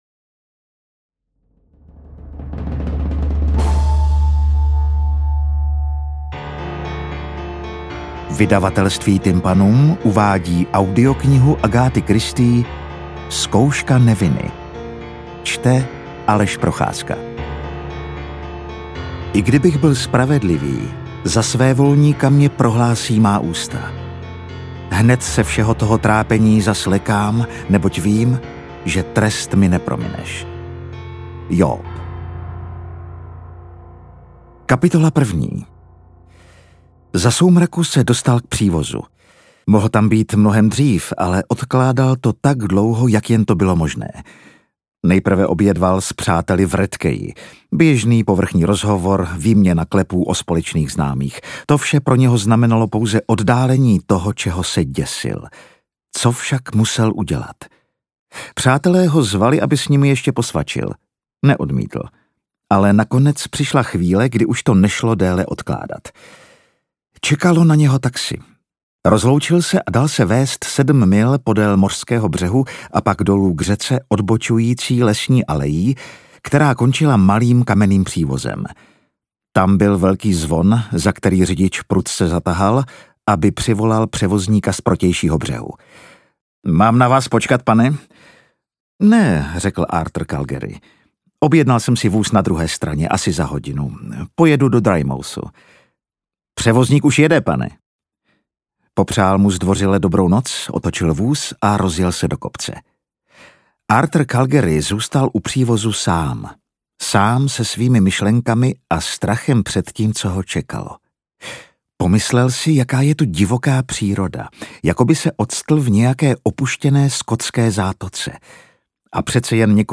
Interpret:  Aleš Procházka